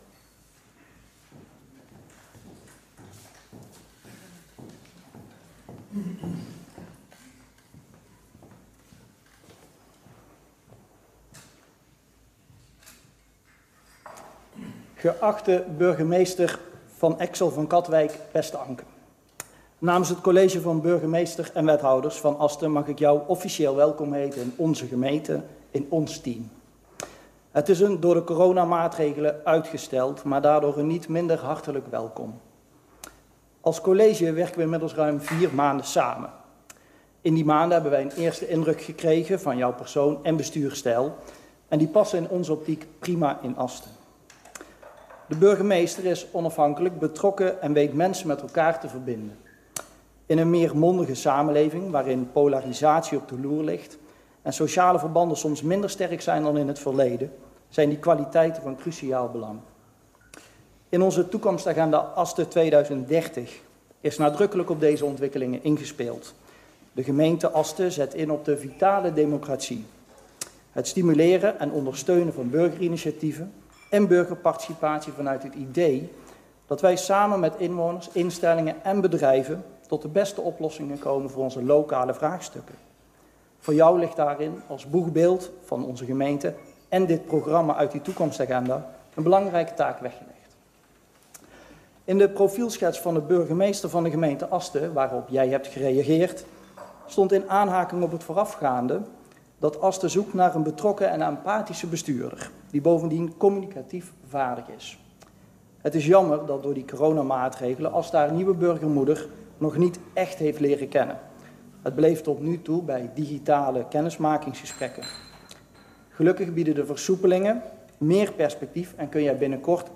Locatie hal gemeentehuis